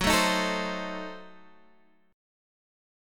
F#dim7 Chord